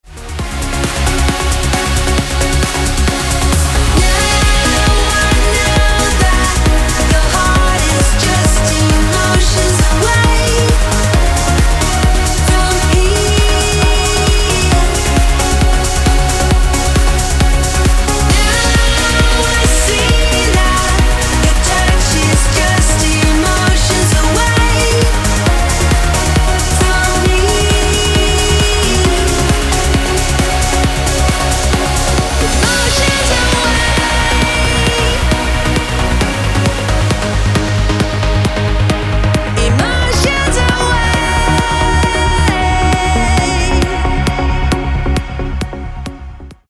громкие
красивые
женский вокал
dance
электронная музыка
club
Trance